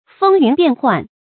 風云變幻 注音： ㄈㄥ ㄧㄨㄣˊ ㄅㄧㄢˋ ㄏㄨㄢˋ 讀音讀法： 意思解釋： 風云：比喻變幻動蕩的局勢；變幻：變化不定。